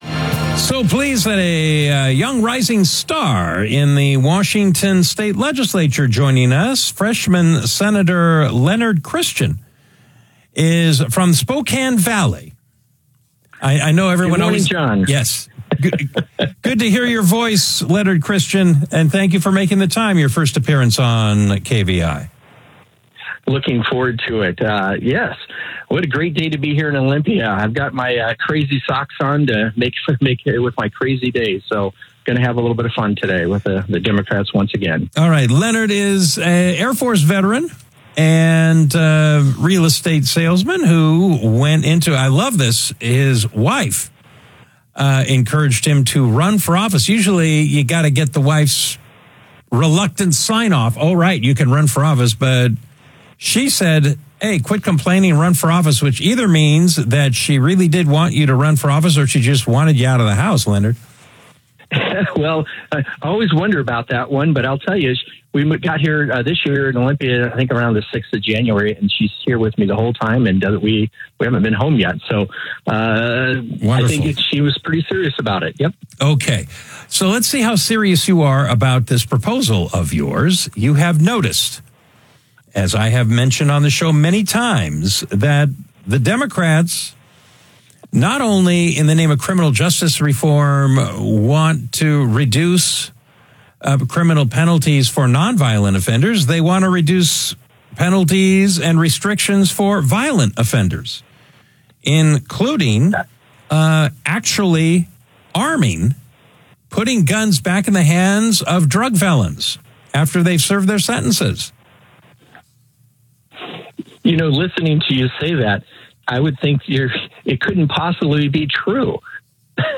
on KVI Radio